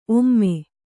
♪ omme